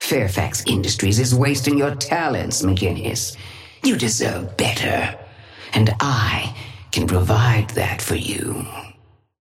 Sapphire Flame voice line - Fairfax Industries is wasting your talents, McGinnis.
Patron_female_ally_forge_start_03.mp3